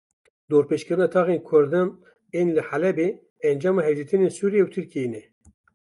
/suːɾɪˈjɛ/